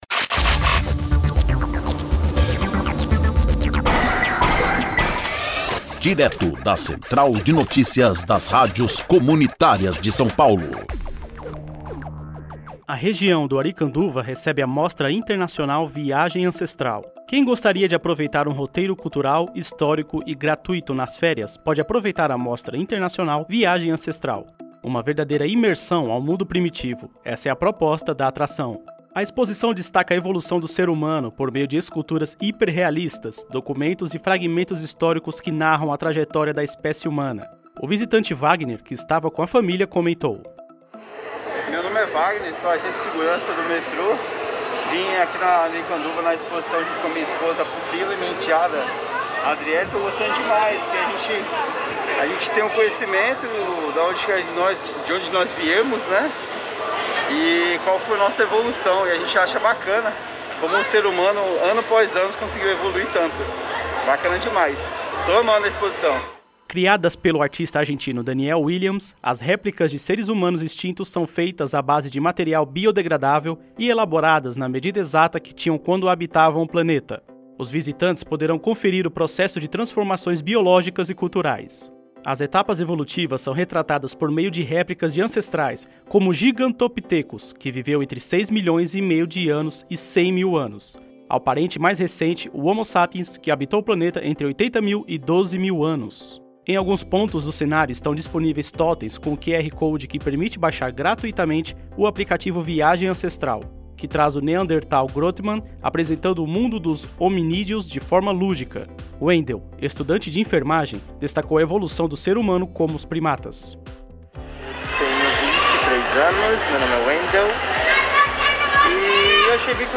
Informativo: Região do Aricanduva recebe a mostra internacional: Viagem Ancestral